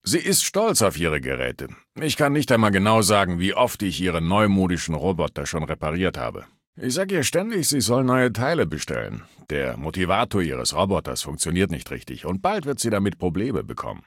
Datei:Maleadult02 mq04 mq04residentmabel 000c01ac.ogg
Fallout 3: Audiodialoge